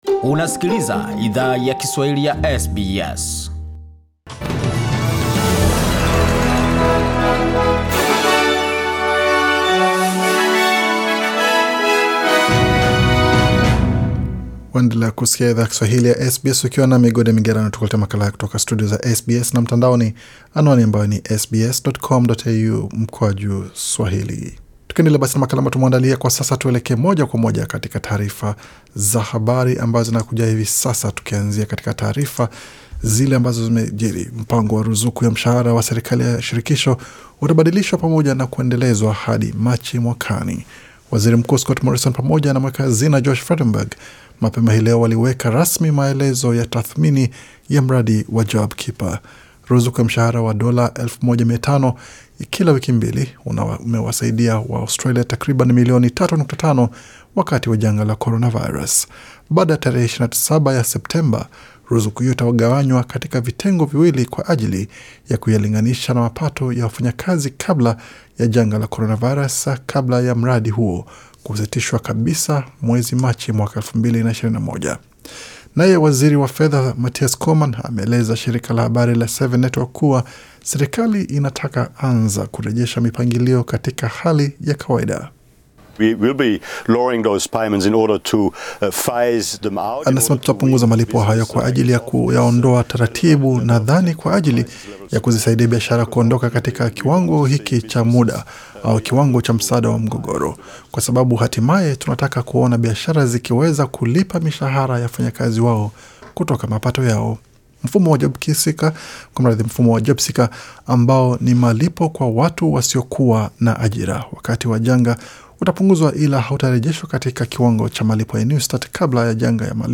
Taarifa ya habari 21 Julai 2020